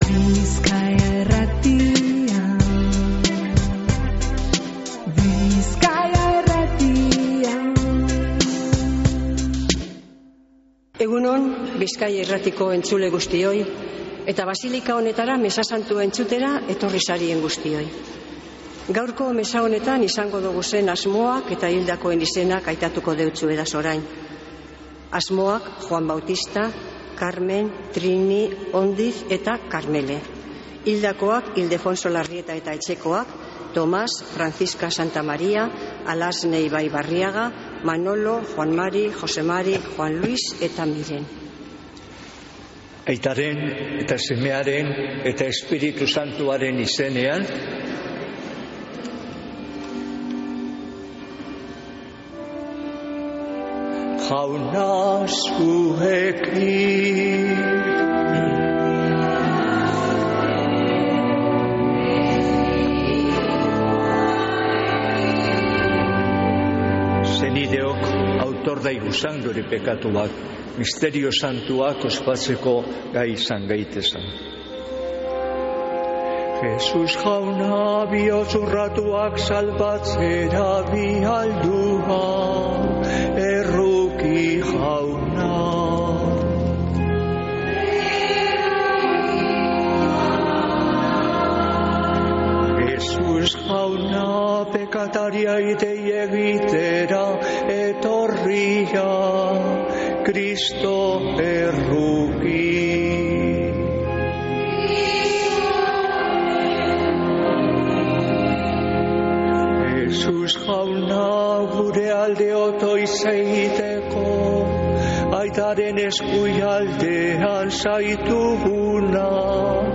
Mezea Begoñako basilikatik | Bizkaia Irratia
Mezea (25-07-29)